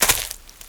STEPS Leaves, Walk 22.wav